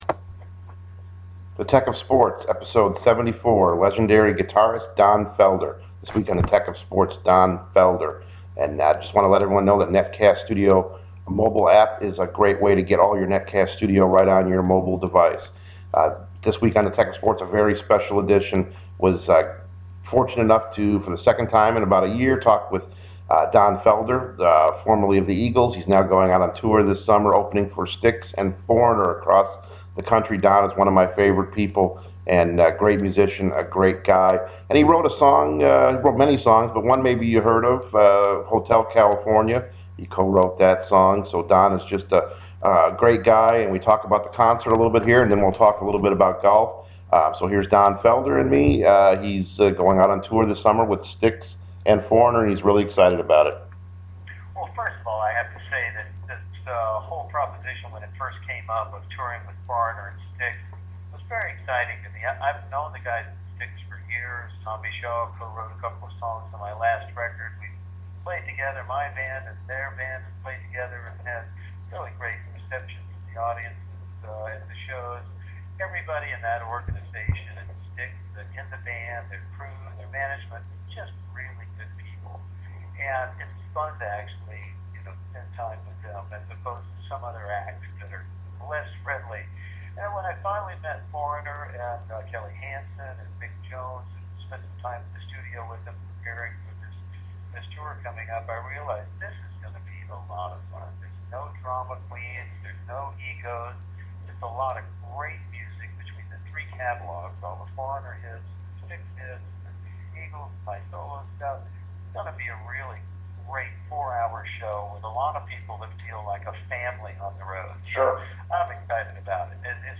Part one of my interview with the legendary Don Felder. The former guitarist of The Eagles.